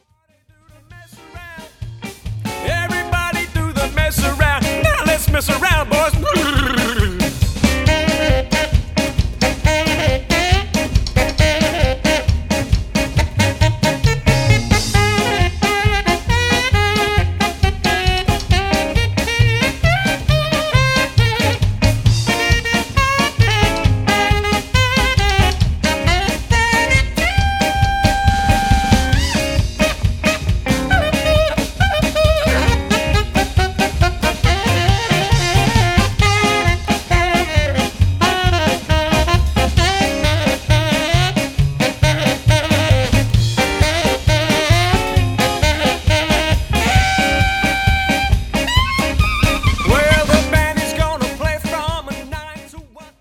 • Classic swing jazz and jive band
• Five-piece instrumental line-up
• Guitar, bass, drums, trumpet, sax, vocals